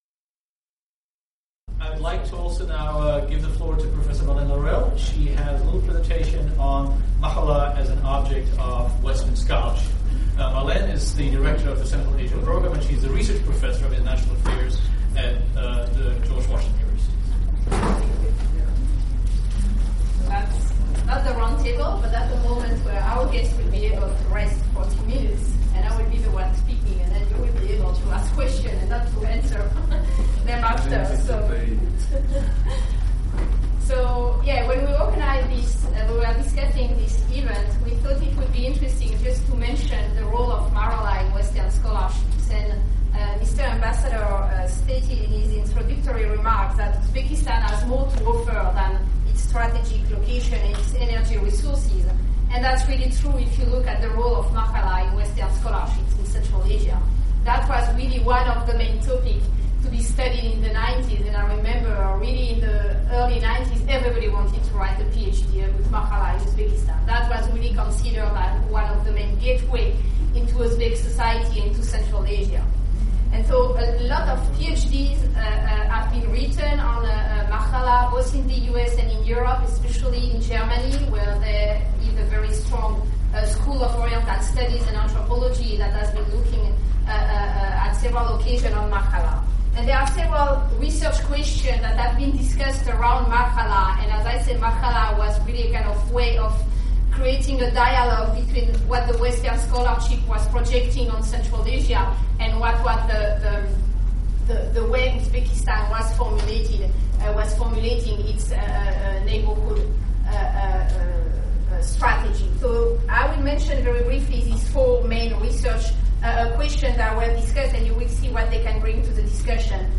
Listen to the discussion on mahalla as an object of Western scholarship. 10,000 mahallas function in the country. What kind of a role do they play in the society? The audio is from an event at Central Asia Program, Elliot School of International Affairs, The George Washington University on May 29, 2014.